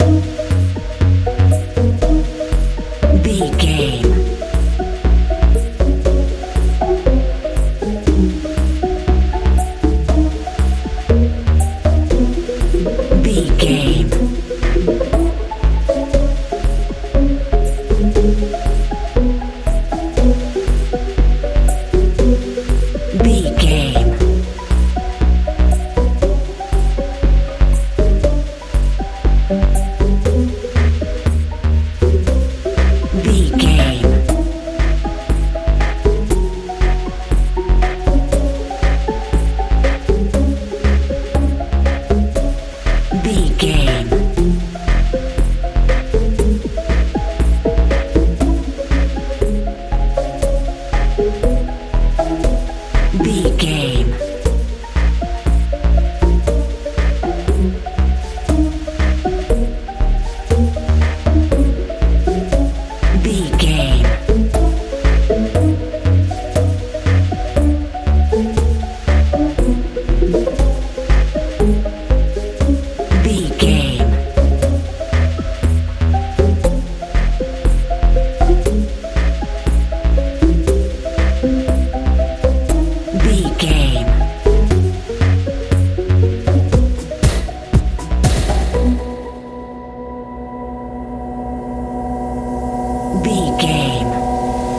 modern dance
Ionian/Major
F♯
fun
playful
bass guitar
synthesiser
drums
80s
strange
mechanical